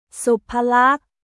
スパラック